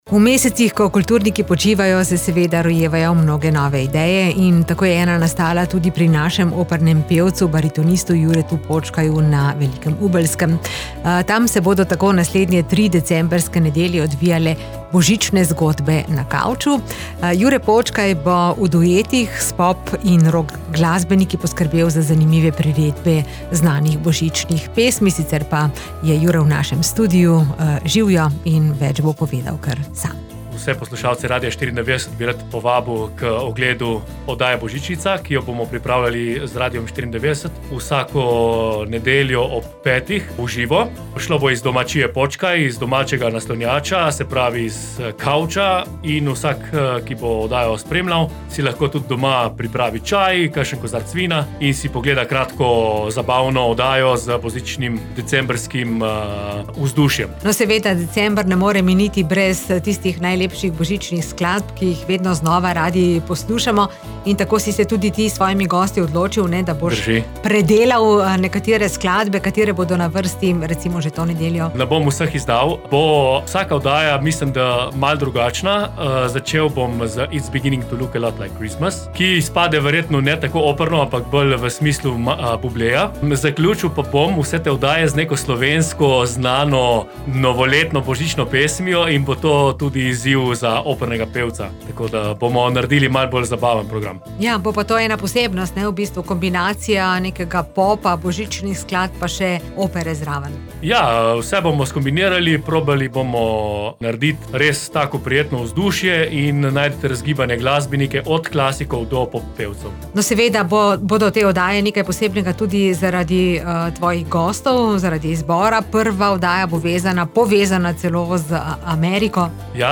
Pogovore z njimi in priredbe nekaterih najlepših božičnih skladb v neobičajnih duetih pa boste lahko poslušali tudi na Radiu 94.